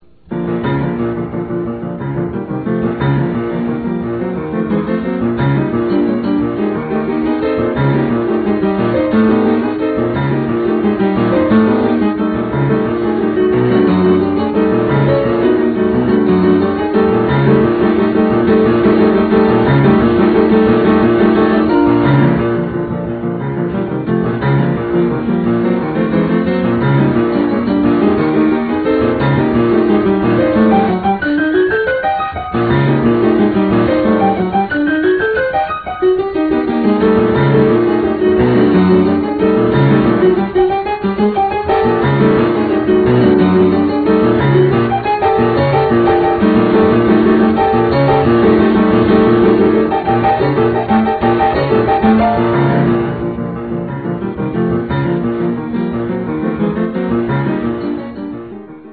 Click here to listen to noises I made on a big black box with little things you can push Get Real Audio Sex: Male Age: 22 IQ: 17 I like this brand of Macaroni and Cheese: Well, that's about all there is to say about me.